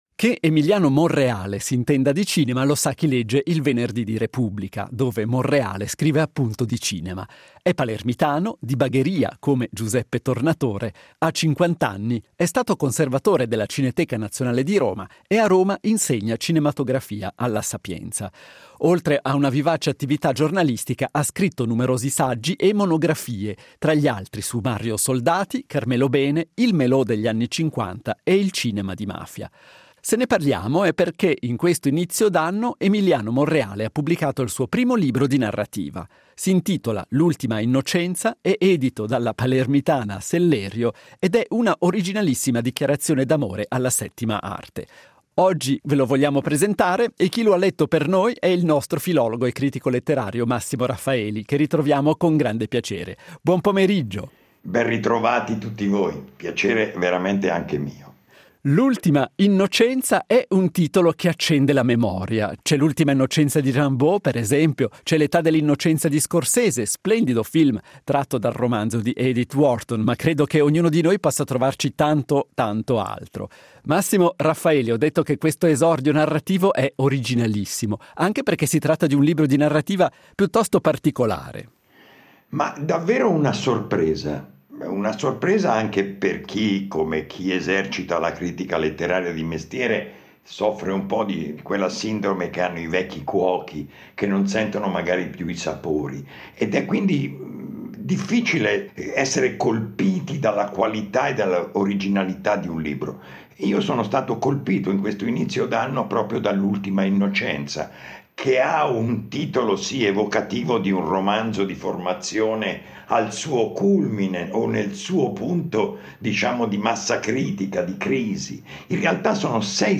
Intervista integrale.